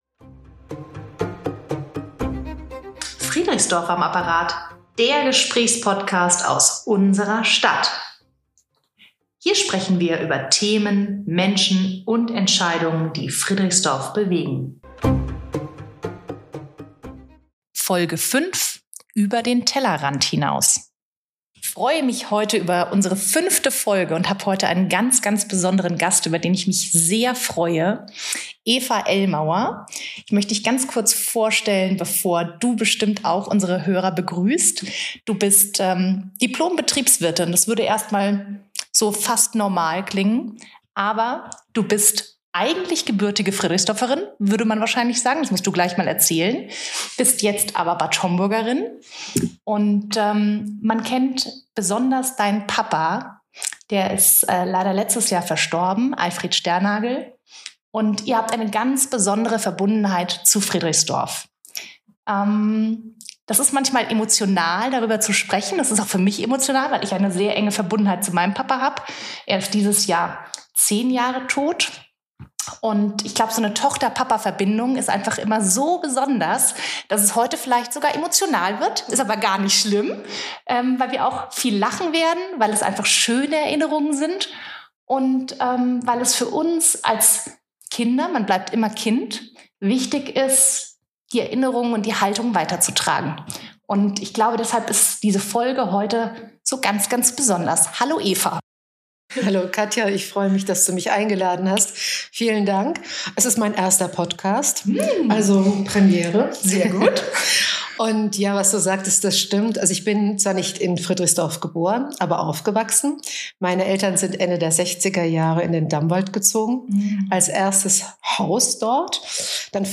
Außerdem sprechen wir über interkommunale Zusammenarbeit, Frauenförderung, Mentorenprogramme für junge Erwachsene und darüber, wie Demokratie vor Ort erlebbar wird. Eine persönliche, offene und stellenweise sehr emotionale Folge über Herkunft, Haltung und Verantwortung.